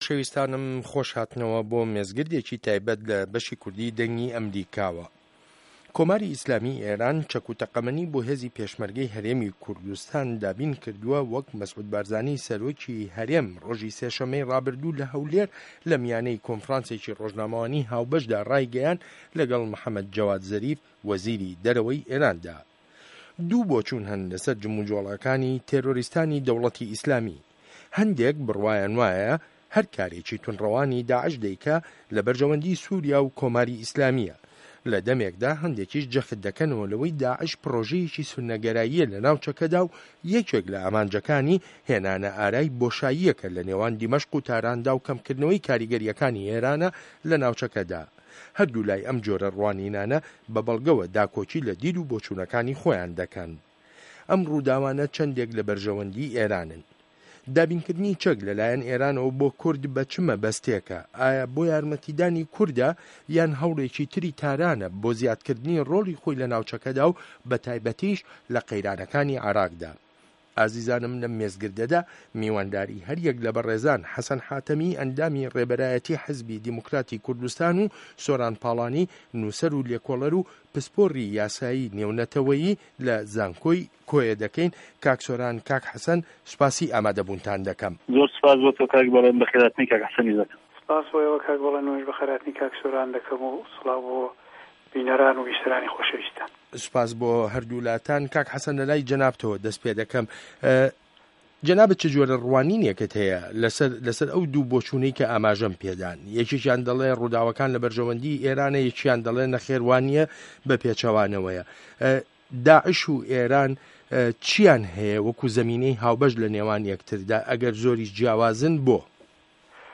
مێزگرد: کۆماری ئیسلامی ئێران و داعش